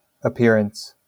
amer. IPA/əˈpɪɹəns/
wymowa amerykańska?/i